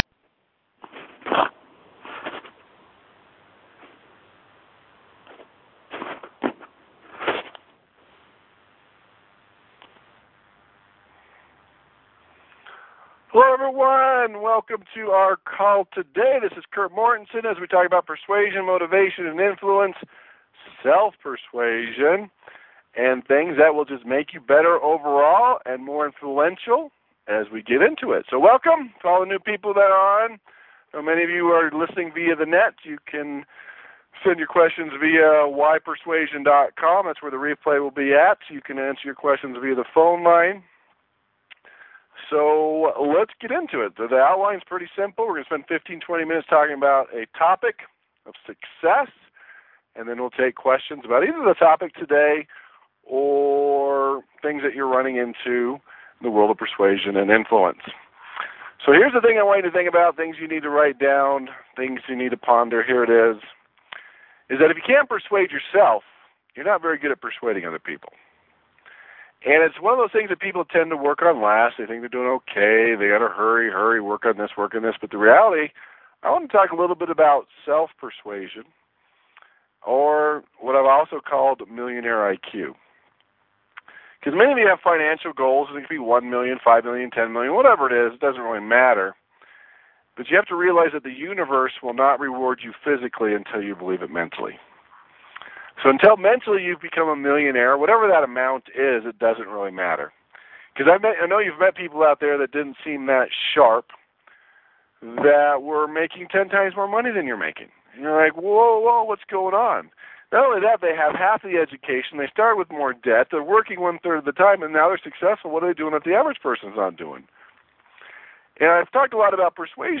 ‹ Meta programs Mood Matters › Posted in Conference Calls